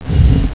push.wav